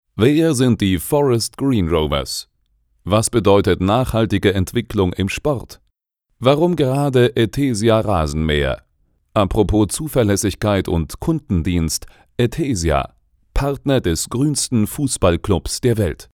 Sprecher mit tiefer, sonorer, warmer Stimme.
Sprechprobe: Industrie (Muttersprache):